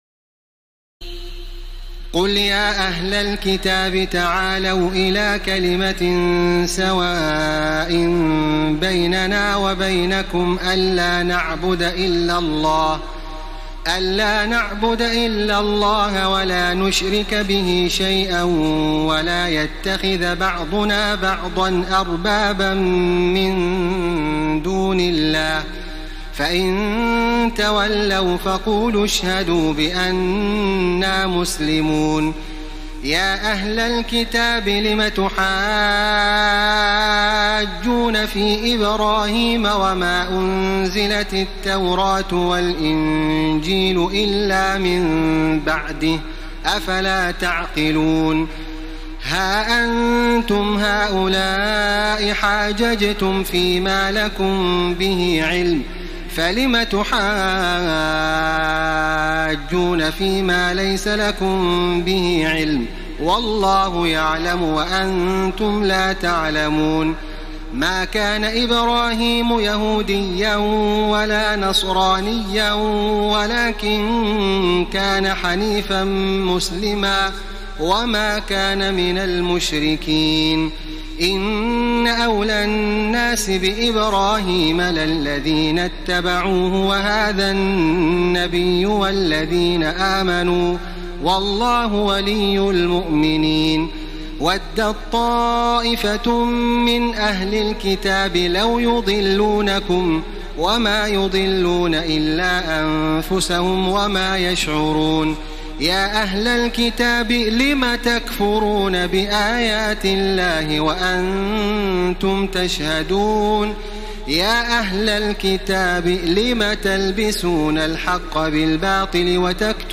تراويح الليلة الثالثة رمضان 1434هـ من سورة آل عمران (64-151) Taraweeh 3st night Ramadan 1434 H from Surah Aal-i-Imraan > تراويح الحرم المكي عام 1434 🕋 > التراويح - تلاوات الحرمين